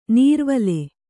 ♪ nīrvale